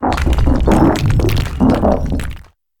Cri de Gigansel dans Pokémon HOME.